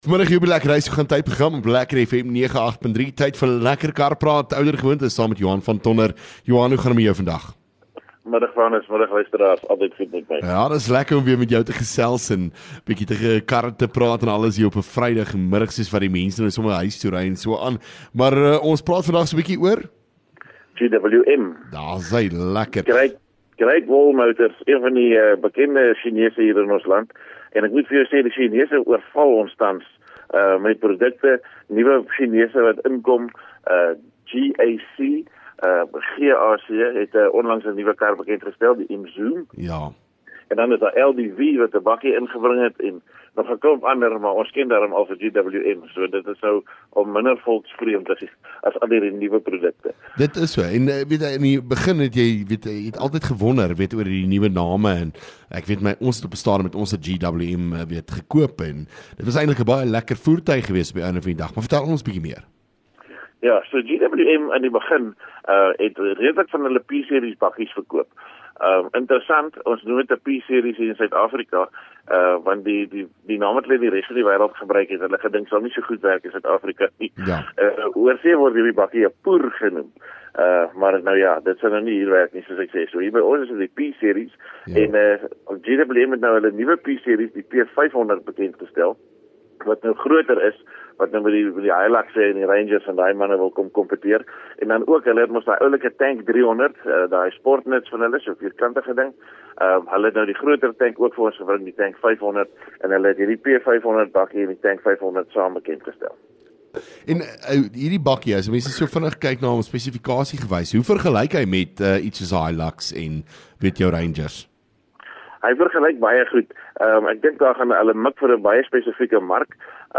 LEKKER FM | Onderhoude 30 Aug LEKKER Kar Praat